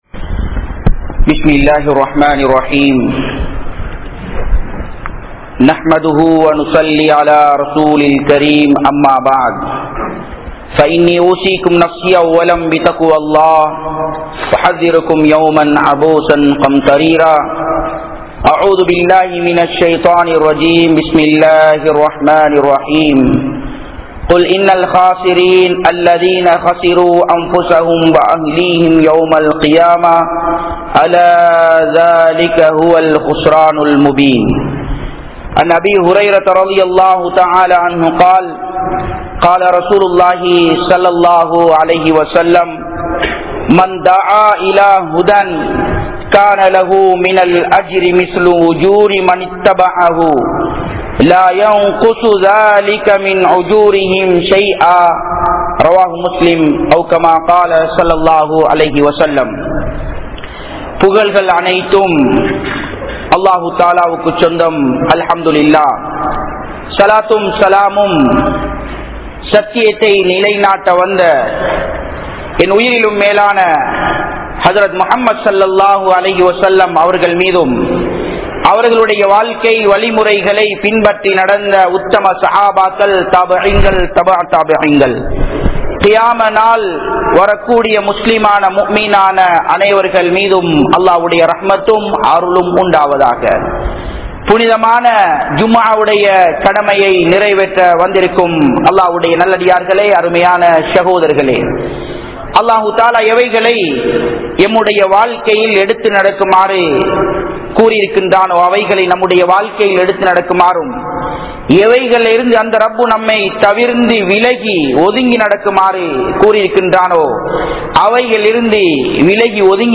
Marumai Naalil NastaValihal (மறுமை நாளில் நஷ்டவாளிகள்) | Audio Bayans | All Ceylon Muslim Youth Community | Addalaichenai